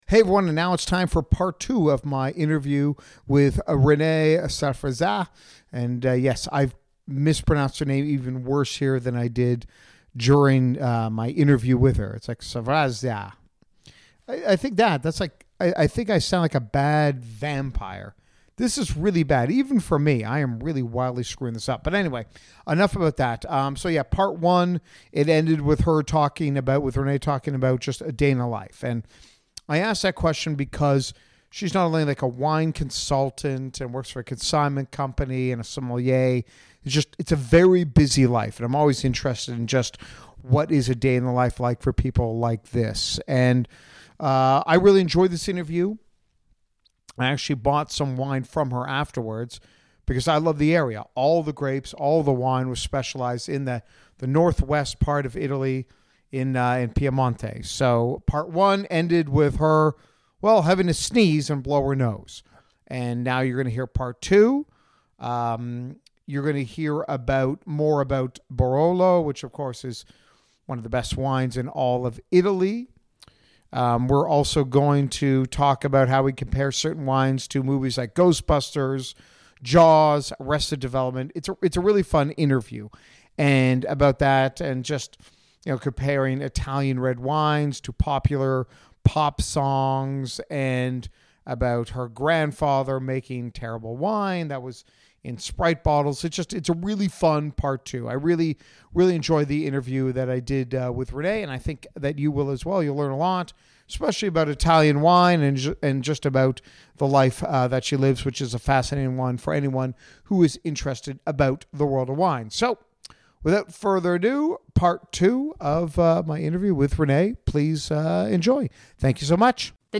In Part II of my interview